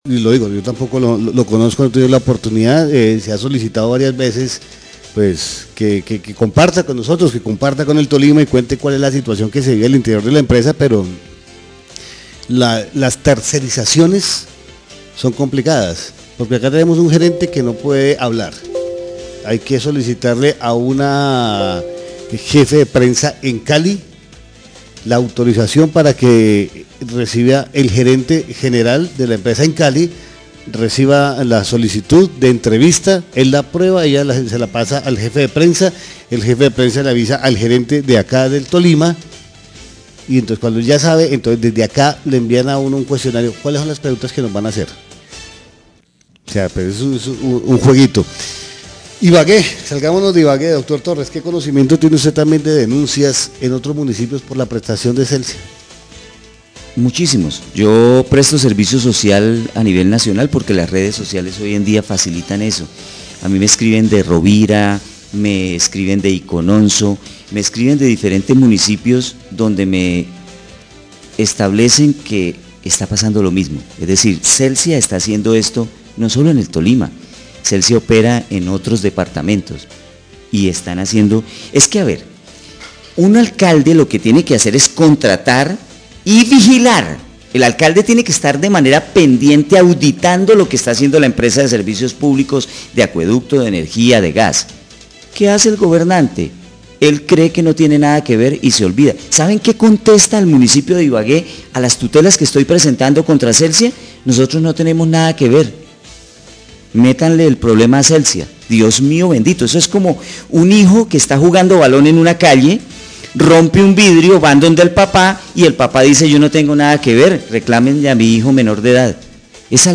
Radio
Periodista explica lo complejo que es comunicarse con Celsia, los filtros y procesos que se hacen y  no ayudan a comunicarse con los directivos de la empresa, dice que hay una tutela contra la alcaldía de Ibagué y ellos derivan a la  empresa de energía  por no permitir el servicio de energía para que los niños se eduquen en el municipio de Ibagué en la parte alta del barrio Ambalá.